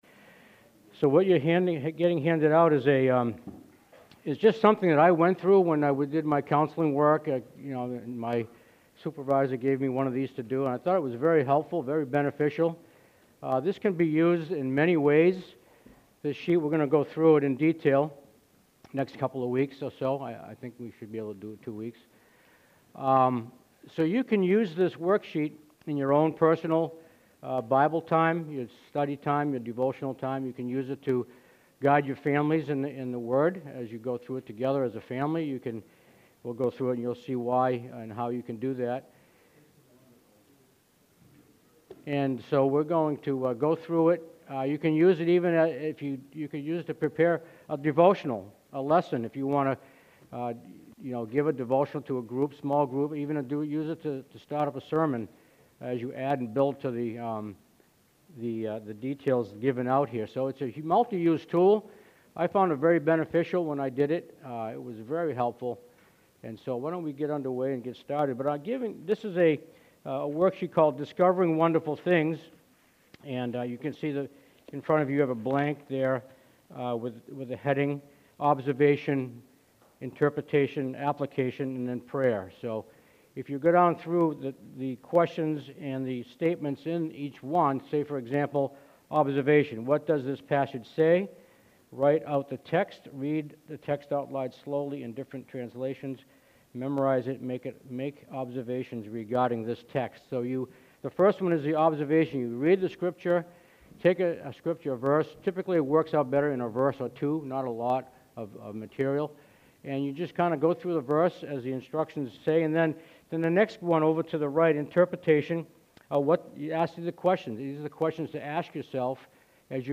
Latest Sermons